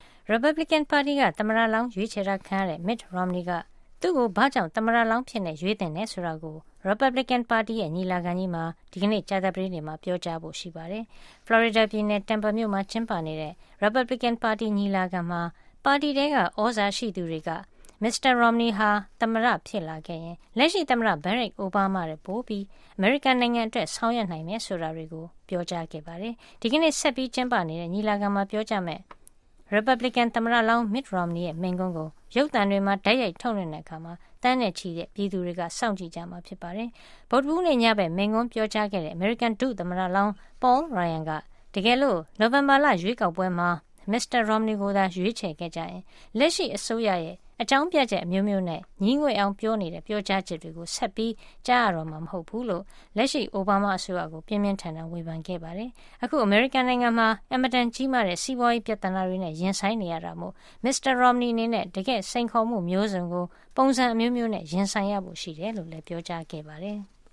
Romney Speech